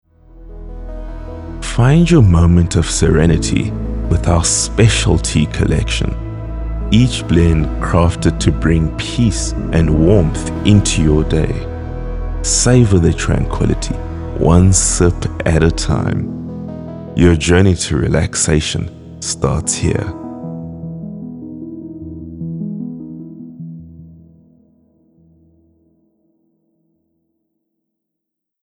authoritative, dramatic, soothing
30-45, 45 - Above
Tea Collection | Serene Soft Sell